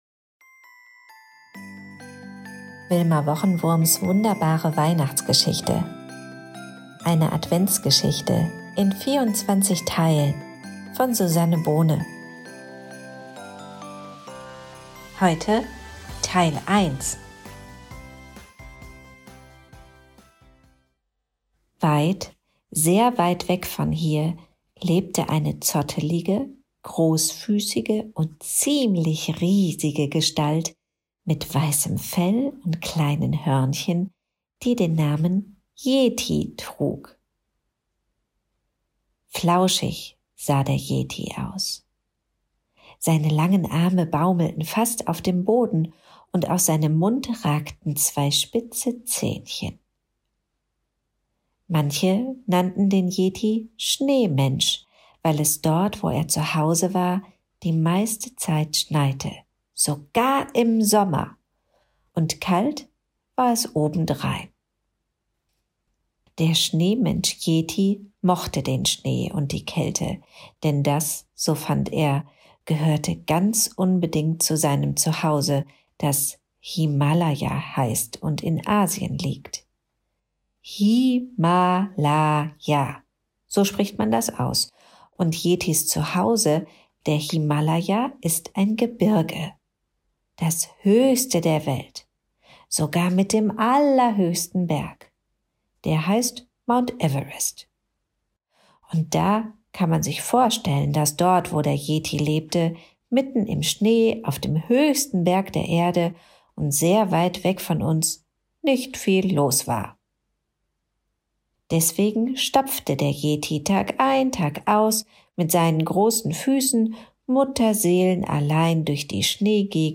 Adventskalender Hörspiel für Kinder
Das Adventskalender Hörbuch für Kinder